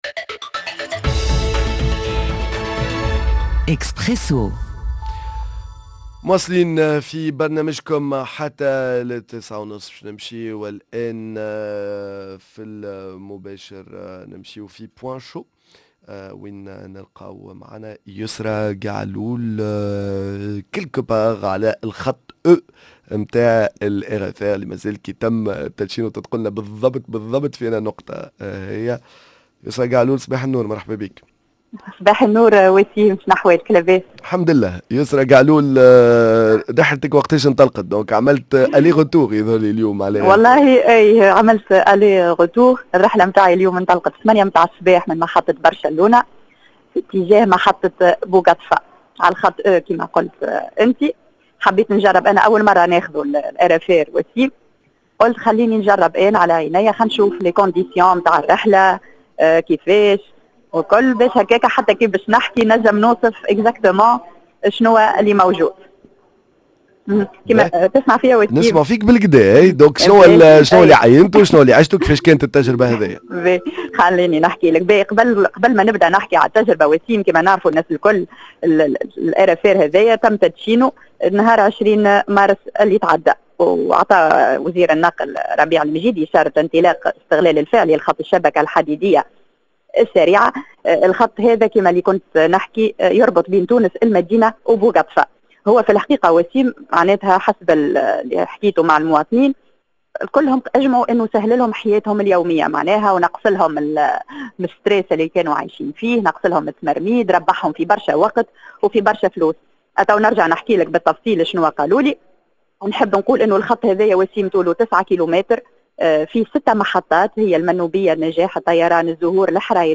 من وسط قطار RFR خط برشلونة-بوقطفة